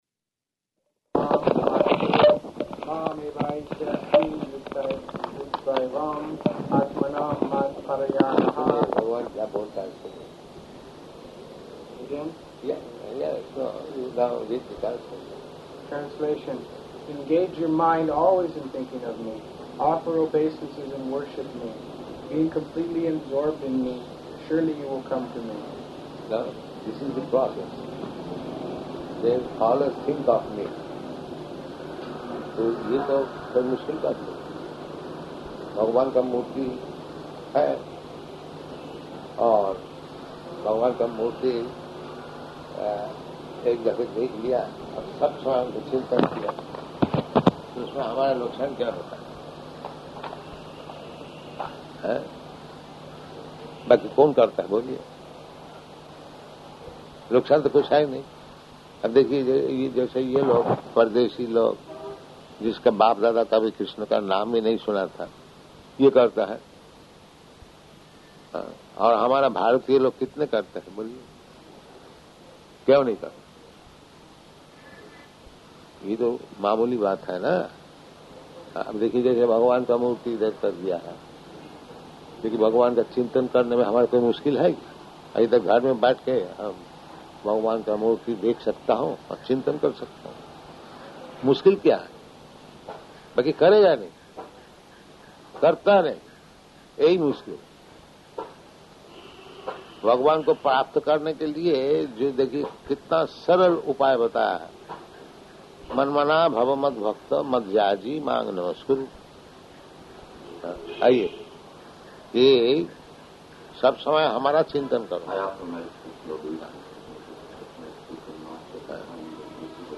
Room Conversation, Bhagavad-gītā 9.34
Room Conversation, Bhagavad-gītā 9.34 --:-- --:-- Type: Conversation Dated: February 25th 1973 Location: Jakarta Audio file: 730225R1.JKT.mp3 Devotee: ...mām evaiṣyasi yuktvaivam ātmānaṁ mat-parāyanaḥ [ Bg. 9.34 ].